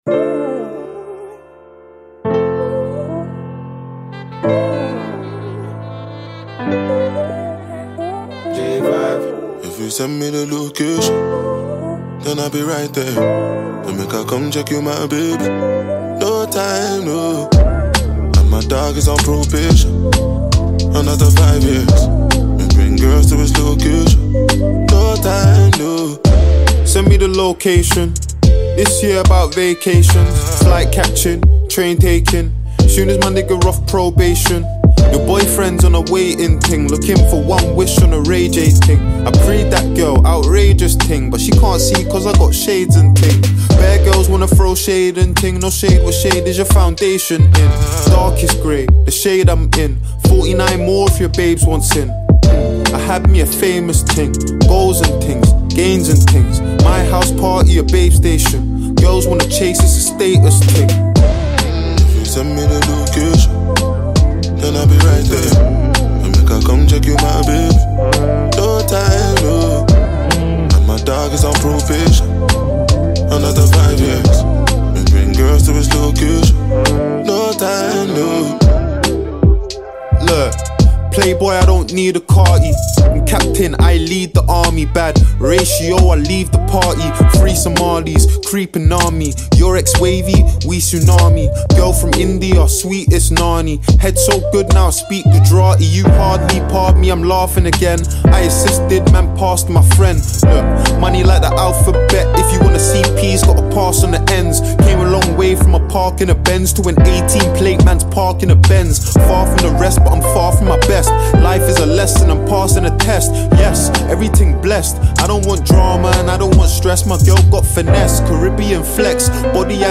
and exceptional vocal delivery for devoted listeners.